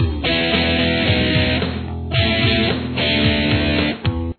Verse
This part is heard in the intro and throughout the verses.